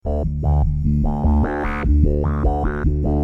Oberheim - Matrix 1000 8